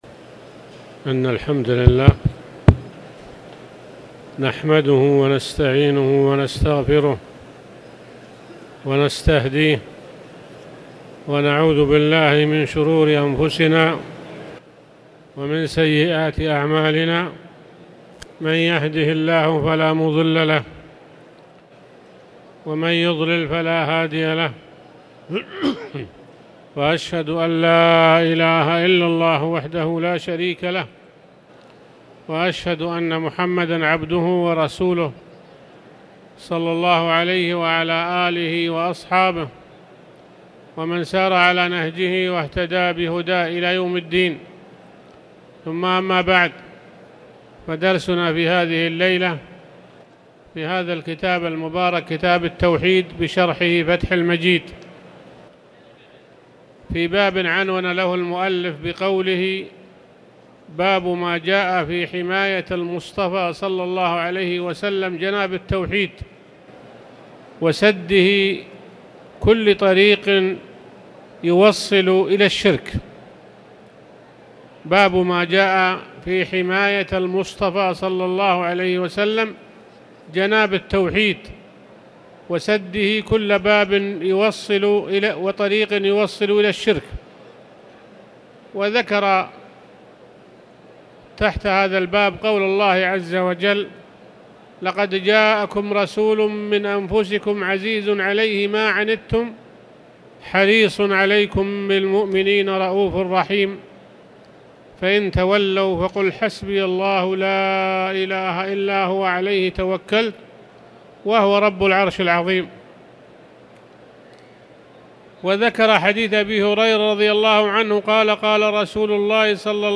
تاريخ النشر ١٥ جمادى الآخرة ١٤٣٨ هـ المكان: المسجد الحرام الشيخ